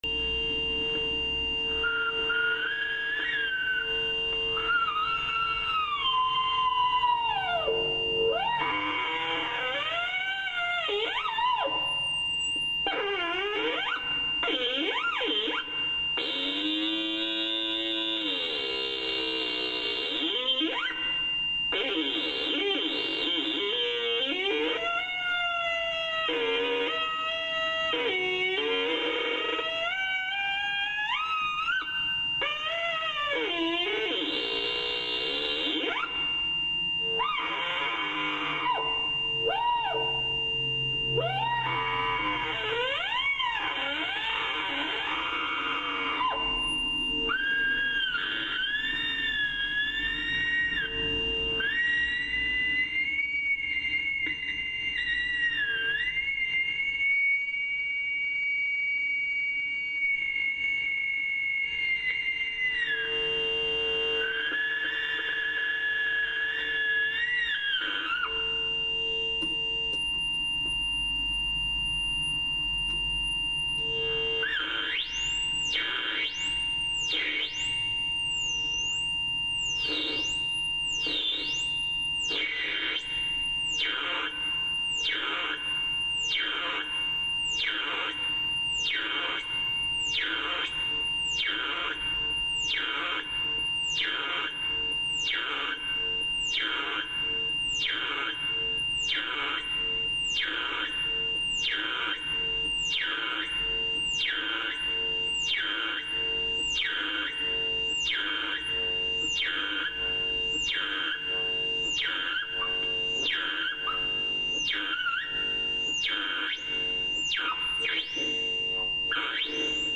SUONO_BOBINA.mp3